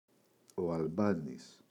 αλμπάνης, ο [aꞋlbanis]